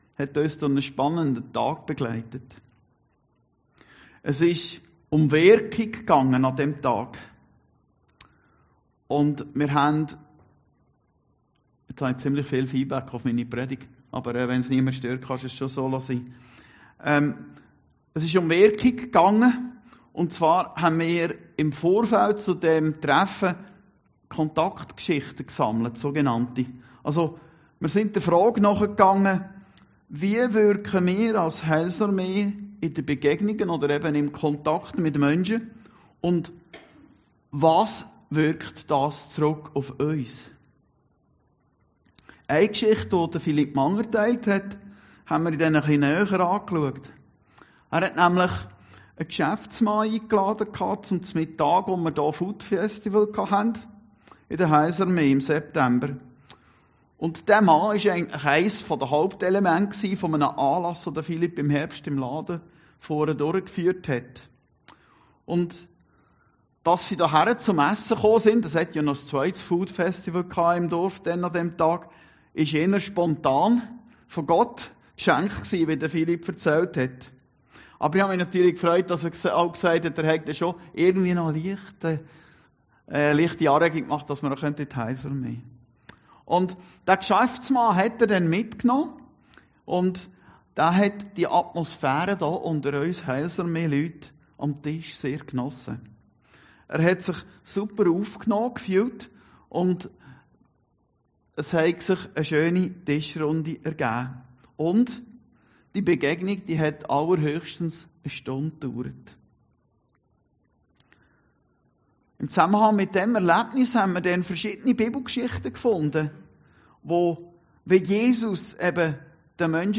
Predigten Heilsarmee Aargau Süd – MIT JESUS AM TISCH